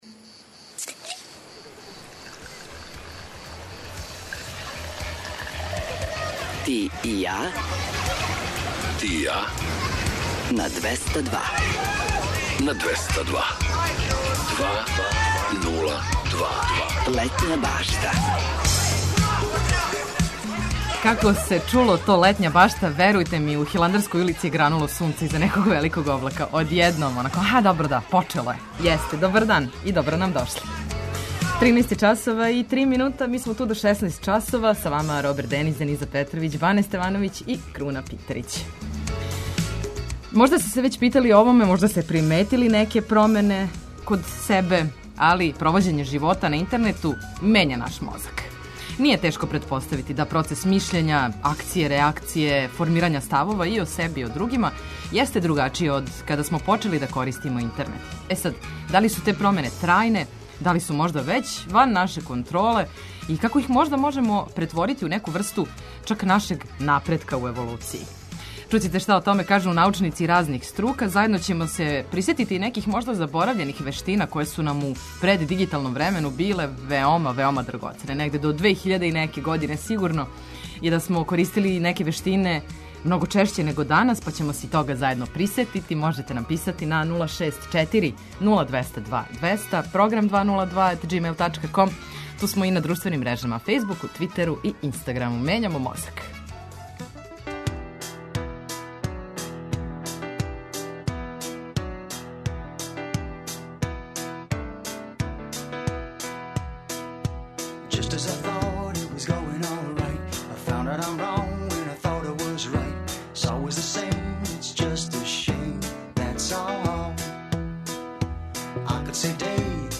У наставку емисије очекује вас више музике, приче о песмама, важним албумима, рођенданима музичара, а завирићемо и у највеће хитове светских топ листа.
Предлажемо вам предстојеће догађаје широм Србије, свирке и концерте, пратимо сервисне информације важне за организовање дана, а наш репортер је на градским улицама, са актуелним причама.